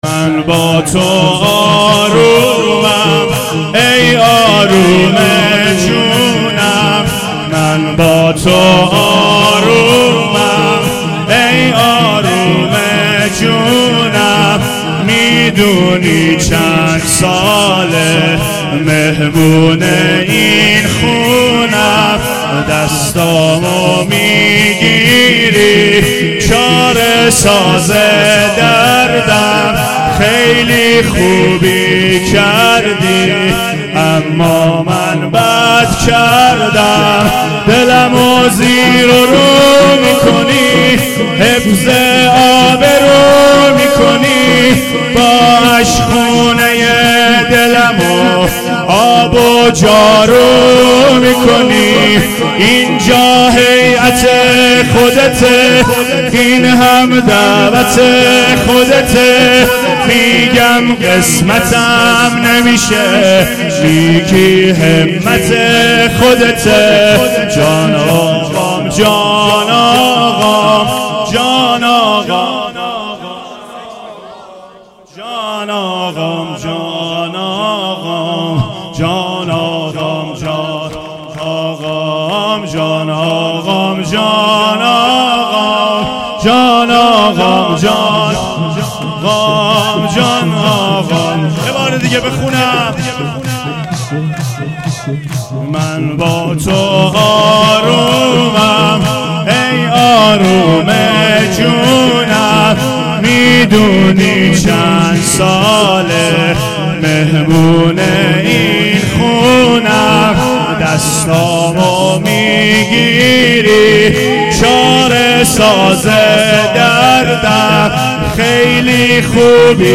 شور شب پنجم فاطمیه